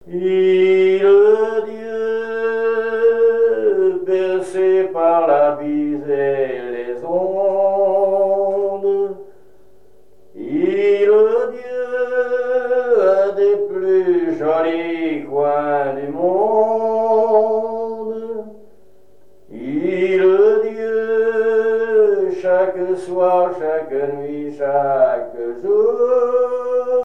Genre strophique
Catégorie Pièce musicale inédite